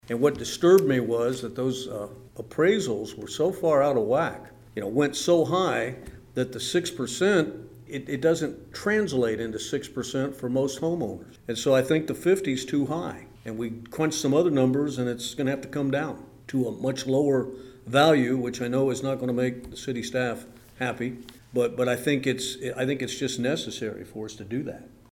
Commissioner Wynn Butler says the city has to do something to offset the roughly 9.5% valuation increases seen this past year.